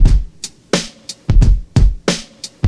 • 89 Bpm Old School Hip-Hop Breakbeat Sample F# Key.wav
Free drum loop - kick tuned to the F# note. Loudest frequency: 793Hz
89-bpm-old-school-hip-hop-breakbeat-sample-f-sharp-key-4KK.wav